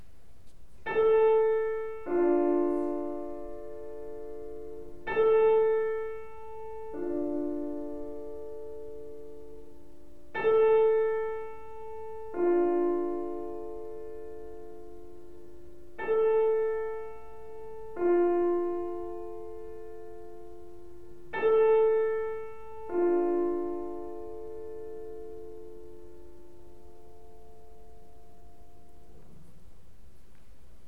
Tuning 'A' Junior Concerto Competition, 3 December 2022